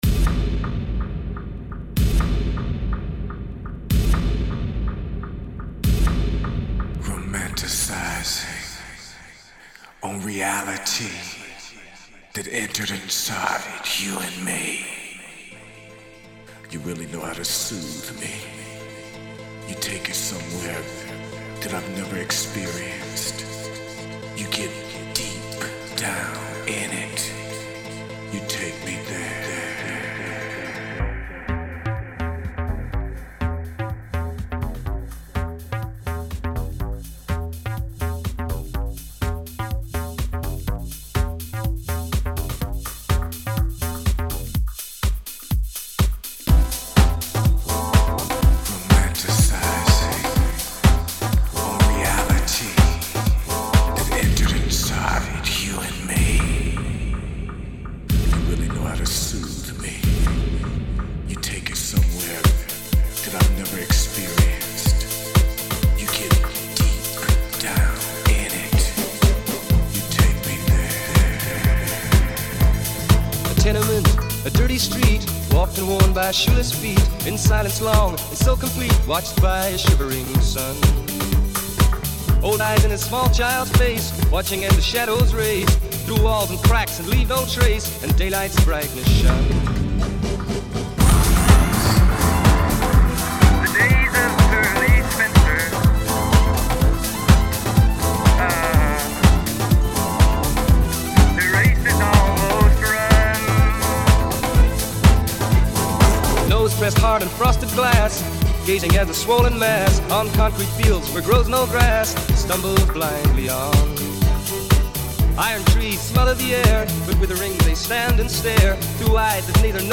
3 new Bootlegs --------